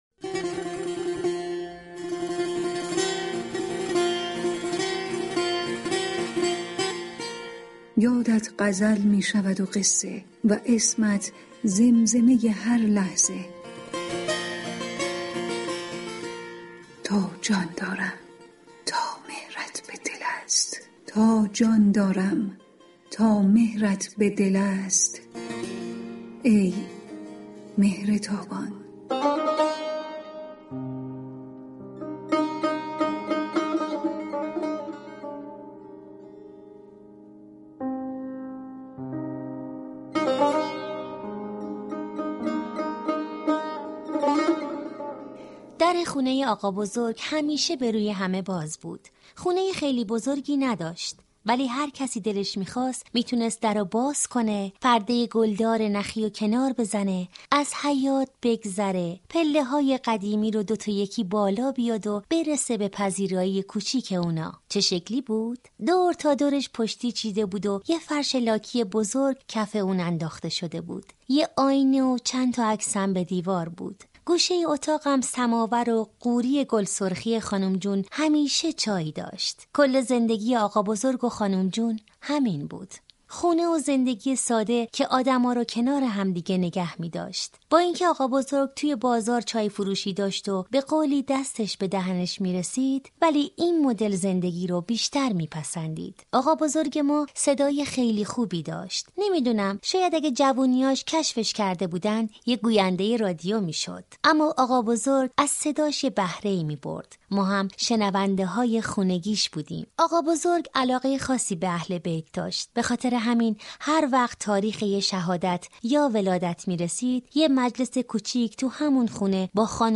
این برنامه با روایت داستان در قالب نمایش در بازه های زمانی امروز، گذشته و گذشته های خیلی دور به شناخت و تاثیر ائمه معصومین در جریان زندگی افراد می پردازد.
«مهرتابان» روایت داستان یك آقابرزگ و خانوم جون از زبان نوه آنها است، این پدربزرگ ومادر برزگ شیرین، خانه ای در انتهای كوچه شقایق دارند كه در آن به روی همه باز است و در مناسبت های مذهبی روضه و سفره نذری برگزار می كنند.